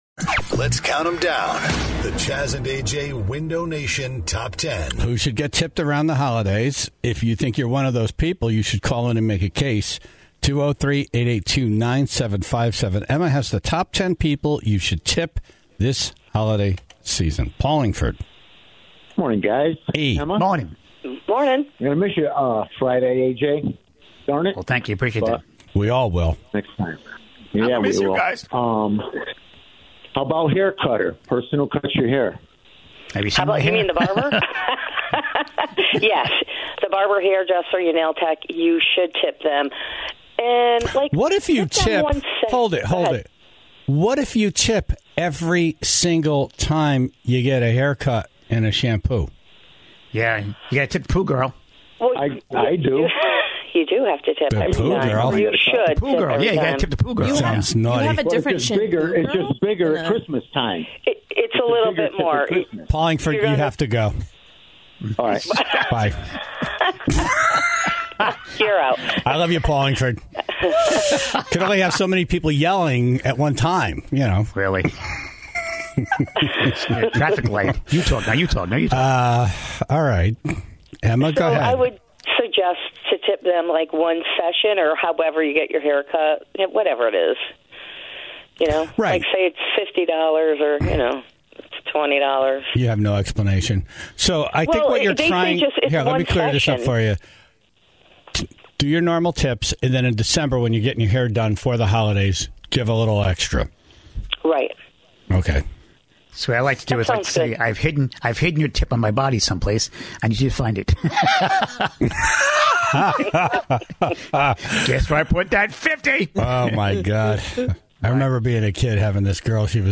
Vinnie Dombrowski of Sponge was on the phone